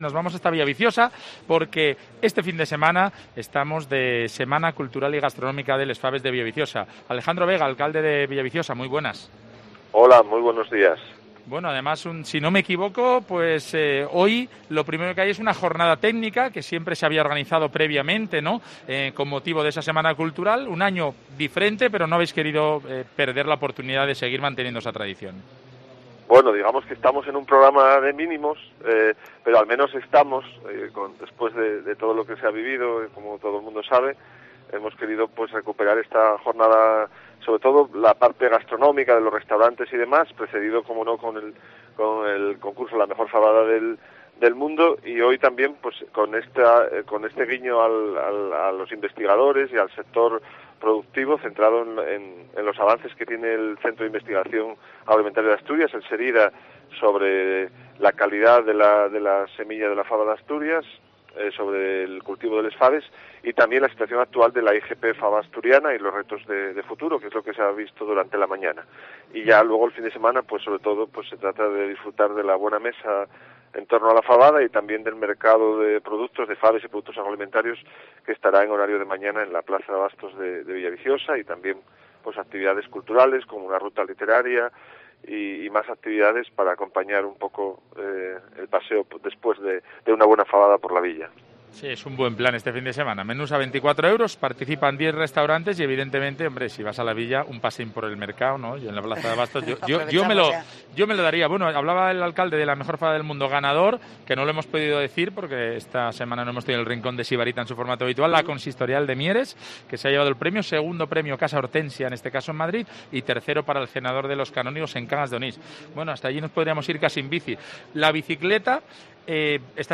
Entrevista al alcalde de Villaviciosa, Alejandro Vega